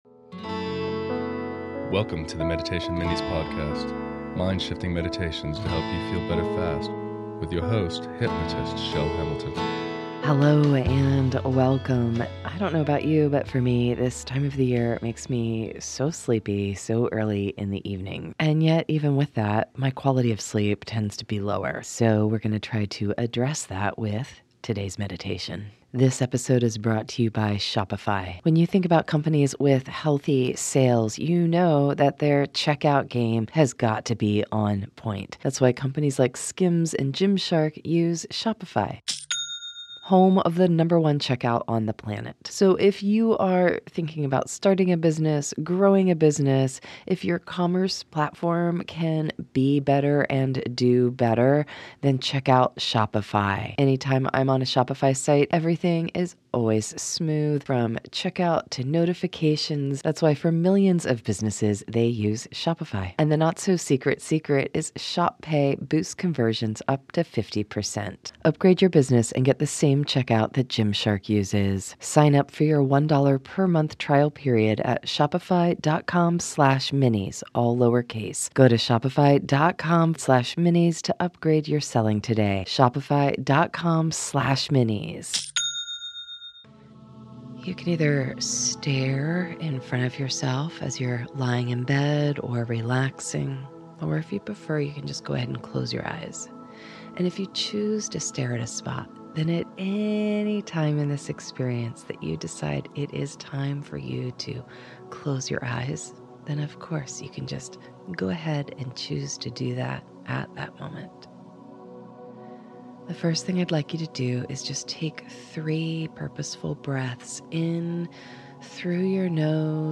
Simple Sleep Meditation